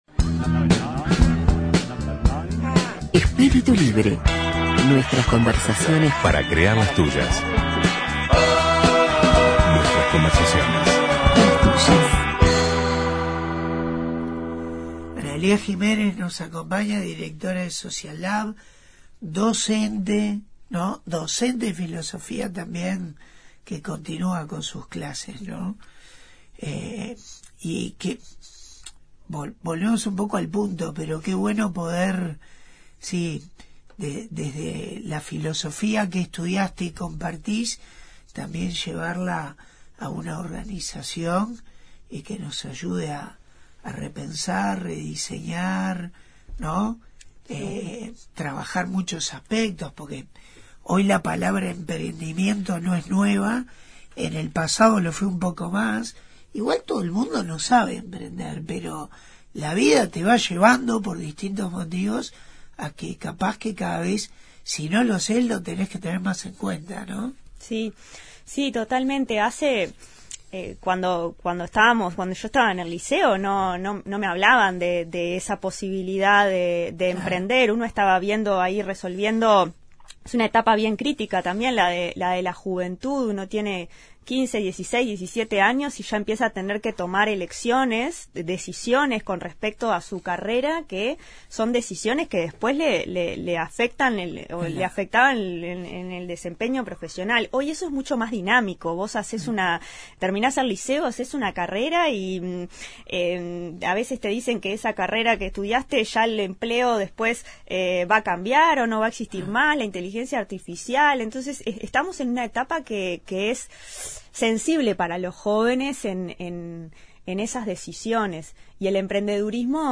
En entrevista con Espíritu Libre